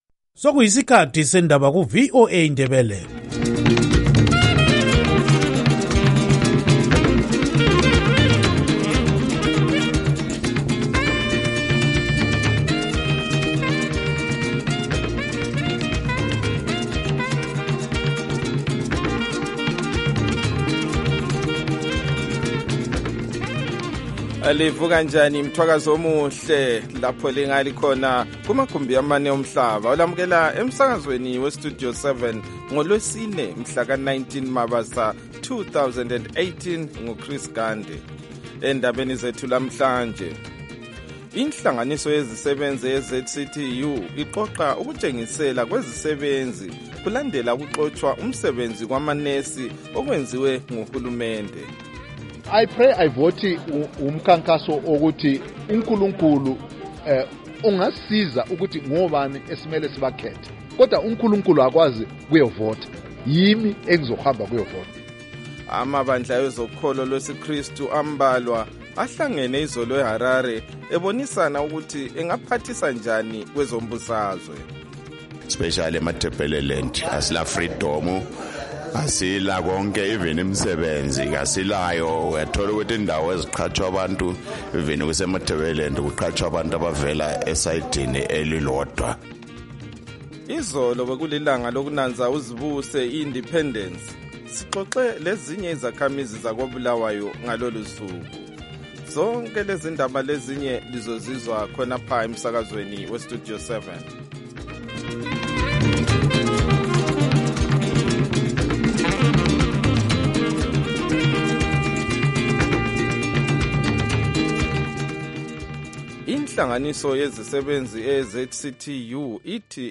Silohlelo lwezindaba esilethulela lona kusukela ngoMvulo kusiyafika ngoLwesine emsakazweni weStudio 7 ngo6:30am kusiyafika ihola lesikhombisa - 7:00am.